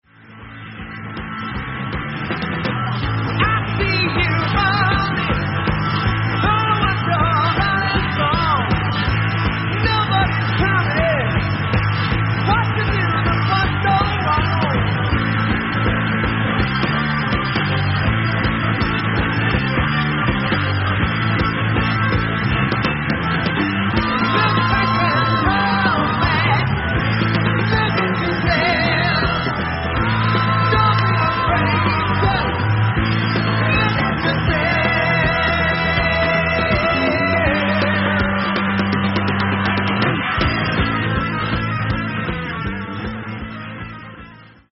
Recorded British Tour January 1973
Mixed at Lansdowne Studios, London
Lead Vocals
Keyboards, Guitars, Vocals
Lead Guitar
Bass
Drums
Recorded by: Pye Mobile Recording Unit